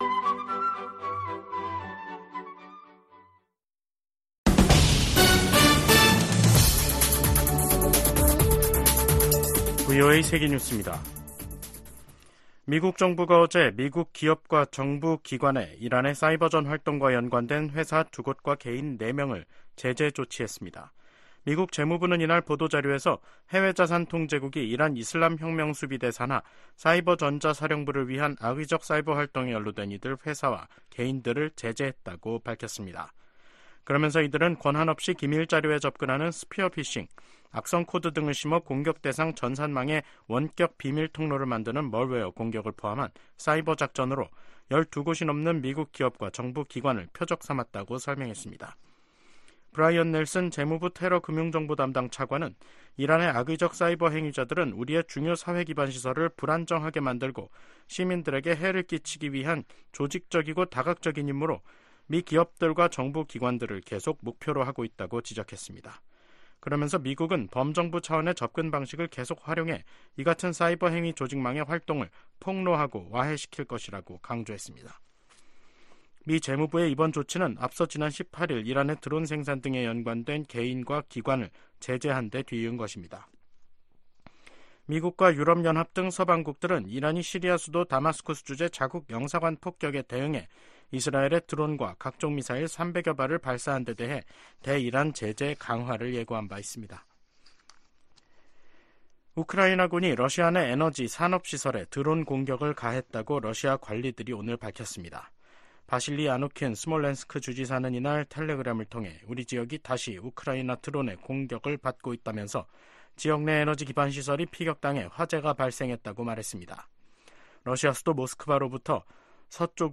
VOA 한국어 간판 뉴스 프로그램 '뉴스 투데이', 2024년 4월 24일 2부 방송입니다. 미 국무부는 북한의 핵반격훈련 주장에 무책임한 행동을 중단하고 진지한 외교에 나서라고 촉구했습니다. 미 국방부는 역내 안보를 위해 한국, 일본과 긴밀히 협의하고 있다고 밝혔습니다.